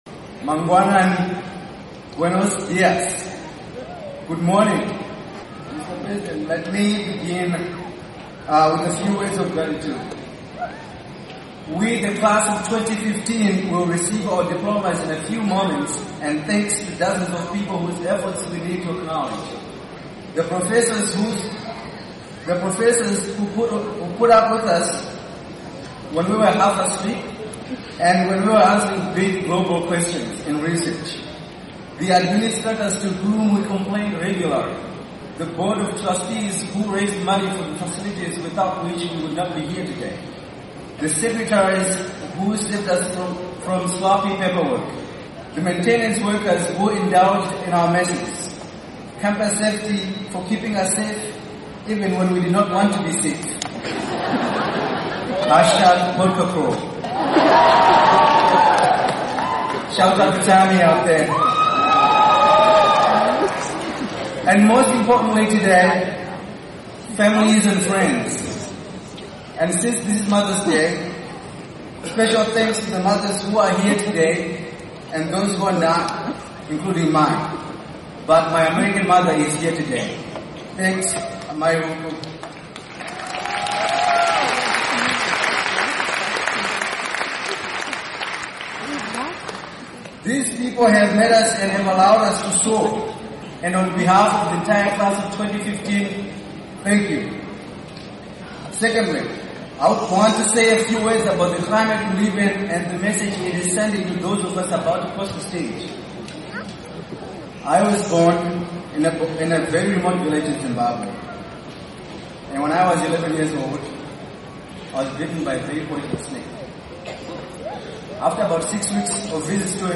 Addressing Students, Guests